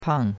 pang4.mp3